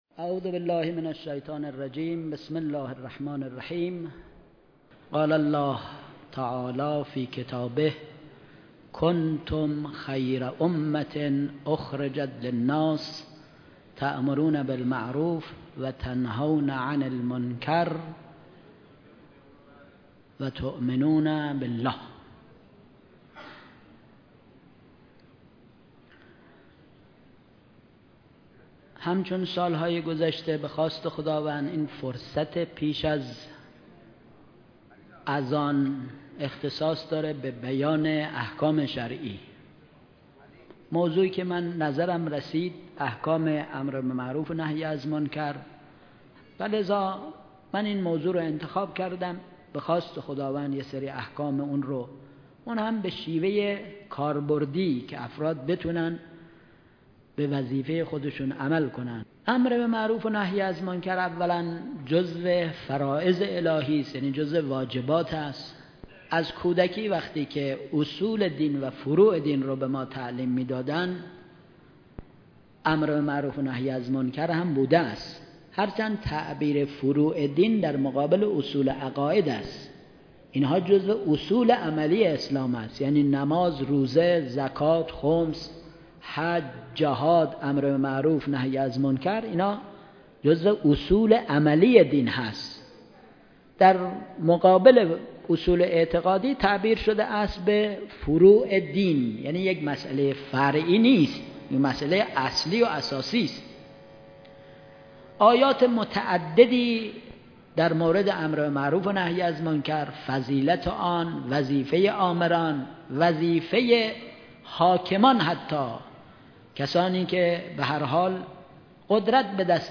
مراسم عزاداری حضرت اباعبدالله الحسین(ع) و یاران باوفای ایشان
اولین شب مراسم عزاداری حضرت اباعبدالله الحسین علیه السلام برگزار شد
بیان احکام